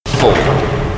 announcer_begins_4sec.mp3